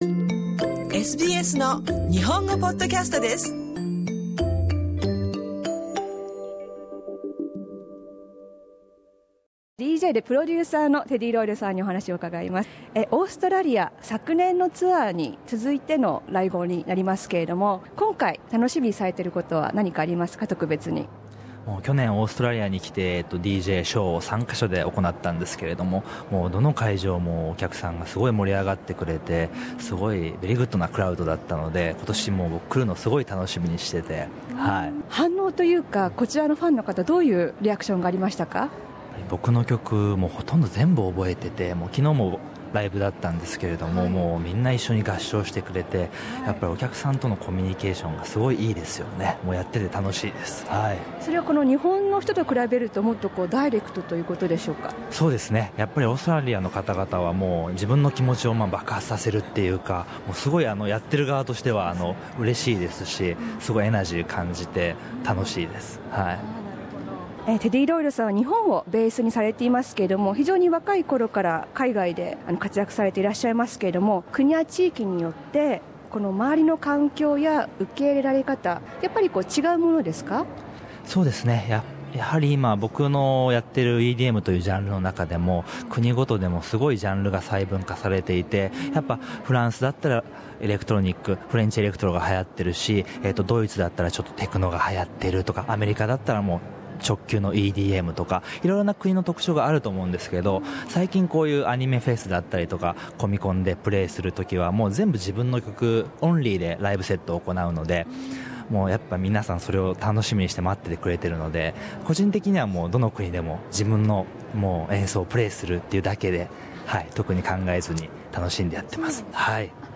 He shares his fond memories at last year’s Australia tour and how he creates music. TeddyLoid also tells us how important it is for him to support young emerging artists while the SNS has expanded at an incredible rate. The interview was broadcast on the 9 th of September 2017.